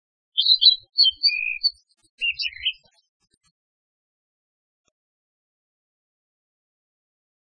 〔キビタキ〕ピッピッ／クルルッ／（ﾋﾋﾋﾋ）（地鳴き）／オーシーツクツク／ポーピッ
ピピル（さえずり）／落葉広葉樹林などに棲息，普通・夏鳥，13.5p，雌雄異色
kibitaki.mp3